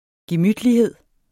Udtale [ geˈmydliˌheðˀ ]